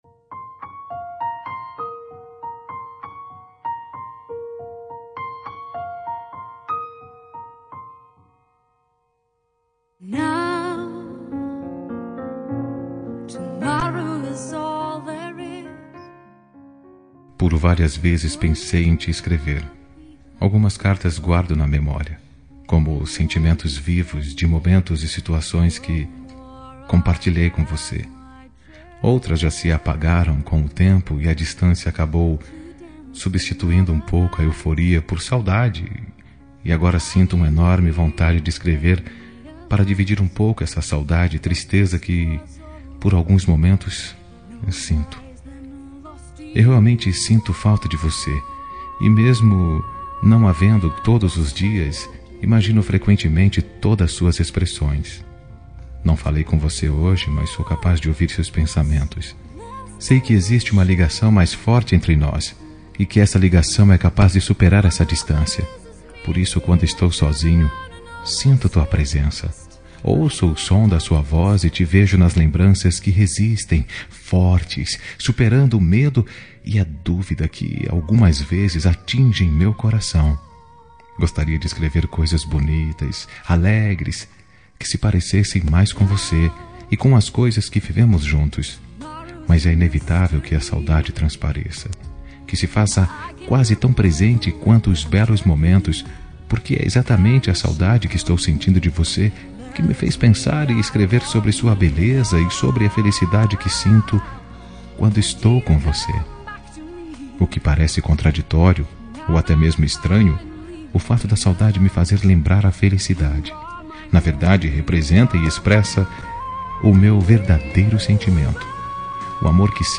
Telemensagem Romântica Distante – Voz Masculina – Cód: 761
761-romantica-masc-distante-linda.m4a